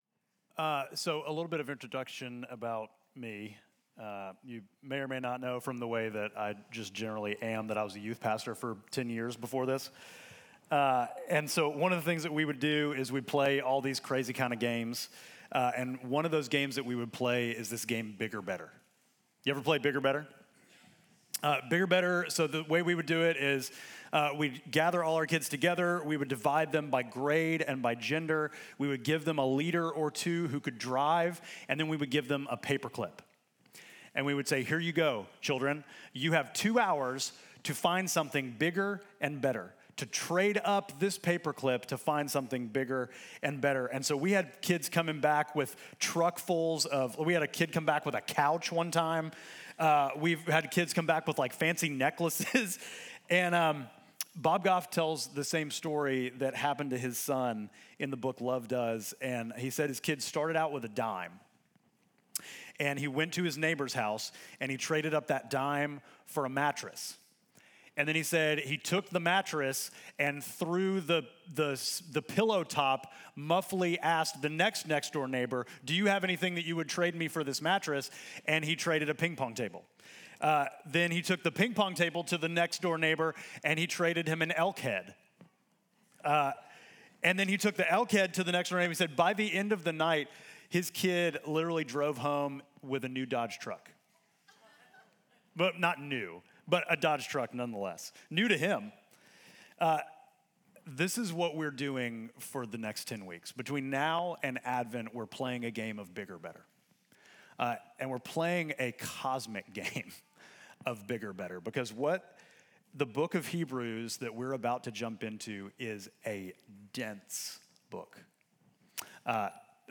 Midtown Fellowship Crieve Hall Sermons Jesus: The Center of the Universe Sep 14 2025 | 00:35:17 1x 00:00 / 00:35:17 Subscribe Share Apple Podcasts Spotify Overcast RSS Feed Share Link Embed